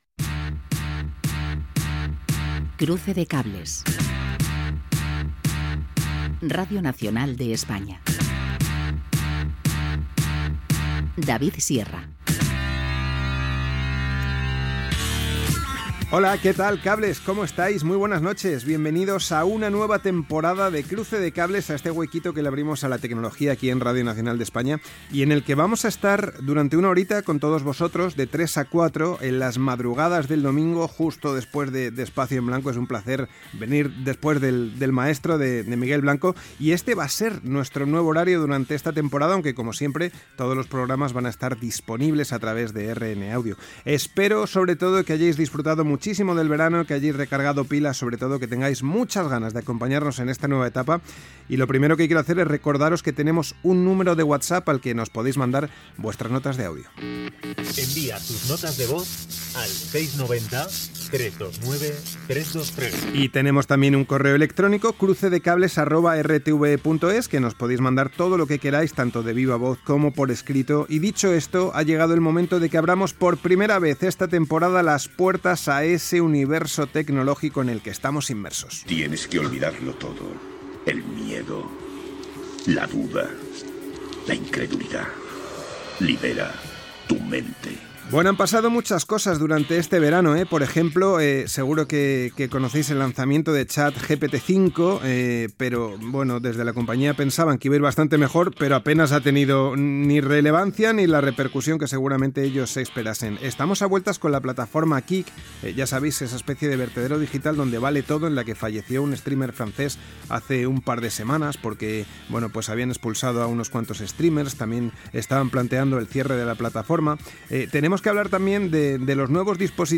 Careta del programa, presentació, avís del canvi d'horari. Whatsapp del programa. Repàs a l'actualitat tecnològica de l'estiu.
Divulgació